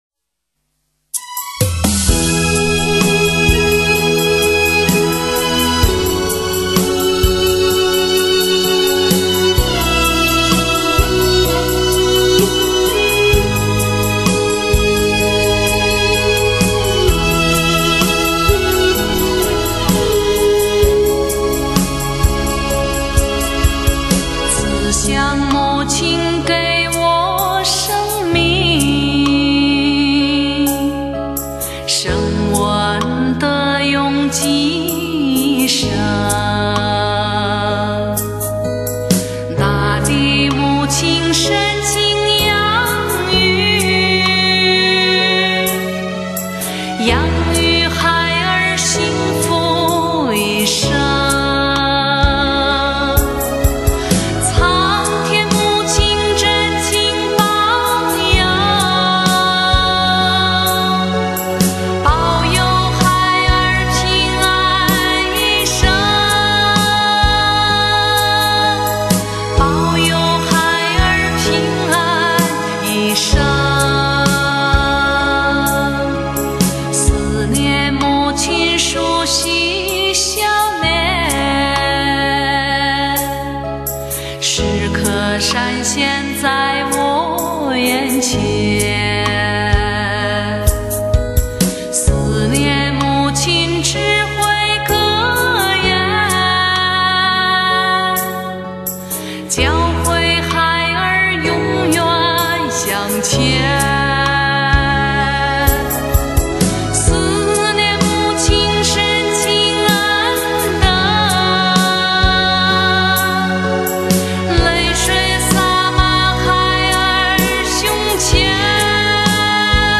蒙古国（包括内蒙）的歌曲深情婉转，须慢慢的细细的品味会有其它歌曲无法比拟的感觉。